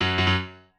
piano4_35.ogg